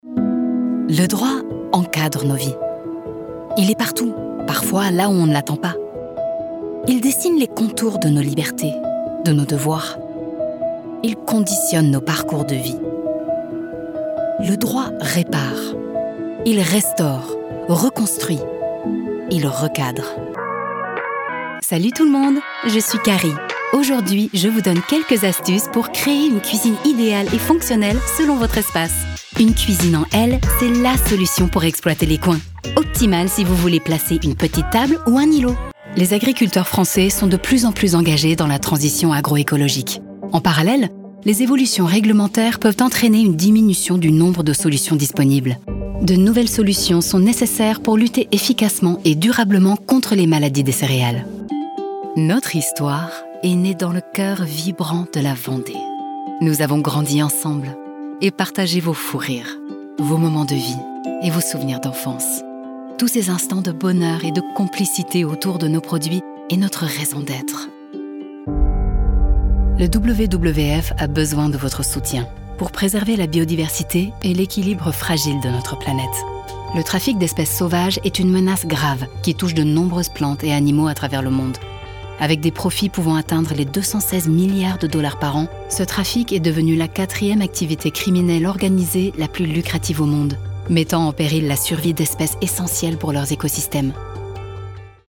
Kommerziell, Zuverlässig, Warm
Unternehmensvideo
- A voice over artist with an eloquent, neutral French.
- Extremely clear diction with perfect enunciation.
- My voice has been described as smooth, warm, friendly and captivating.